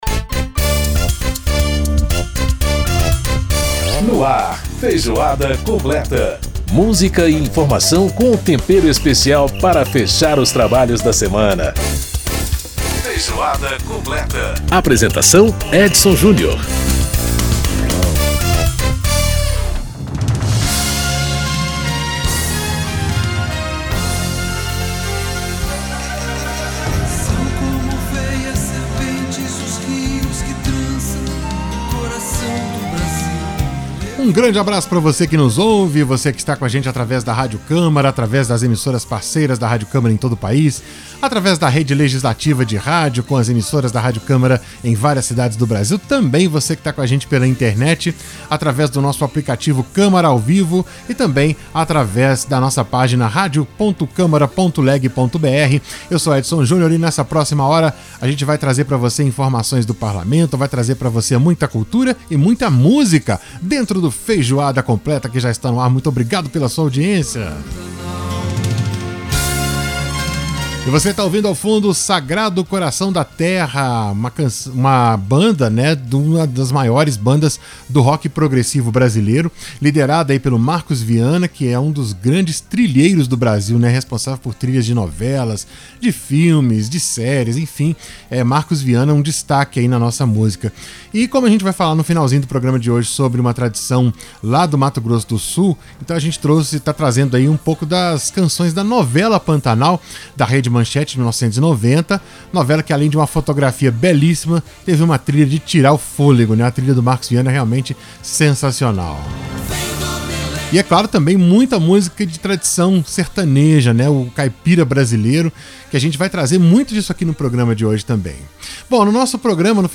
A ideia agora é discutir uma regulamentação permanente. Para entender mais sobre o assunto, conversamos com a Presidente da frente, deputada Adriana Ventura (Novo-SP).